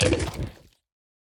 minecraft / sounds / mob / sniffer / eat3.ogg
eat3.ogg